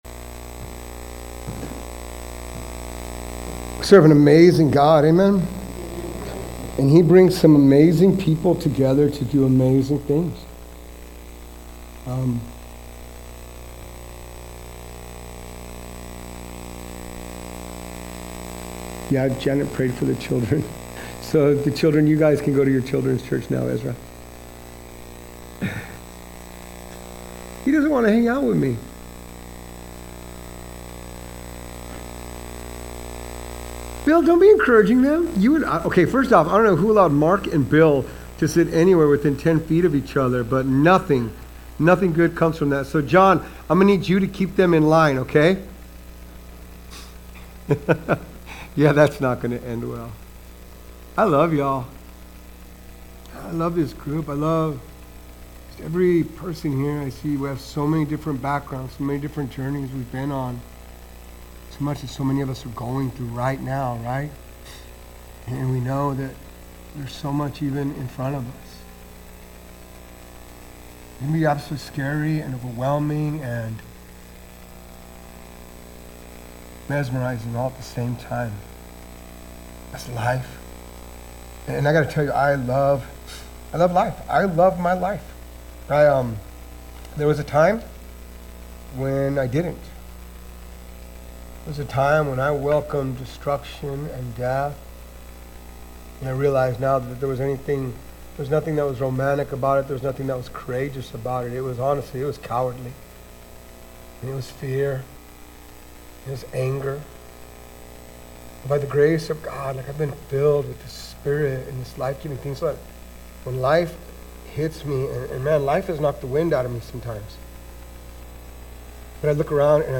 Sermons | Mercy Springs Church of the Nazarene
We're so excited to welcome you to this Sunday's worship service!